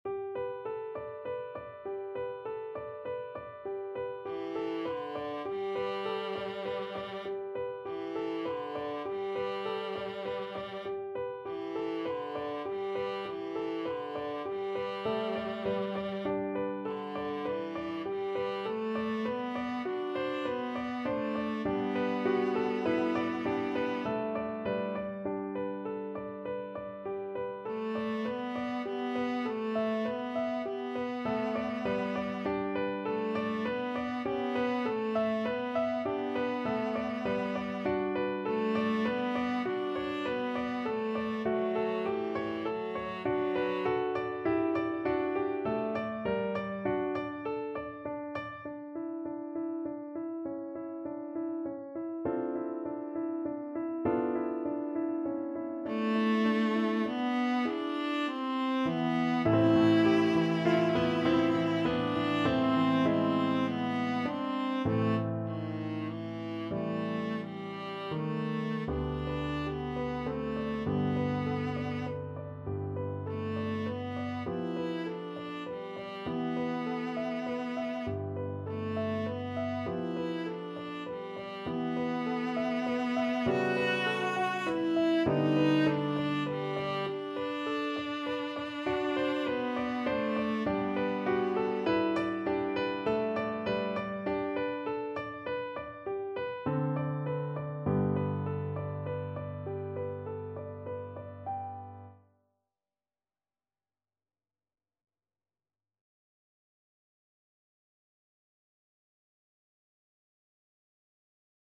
~ = 120 Allegretto
9/4 (View more 9/4 Music)
Classical (View more Classical Viola Music)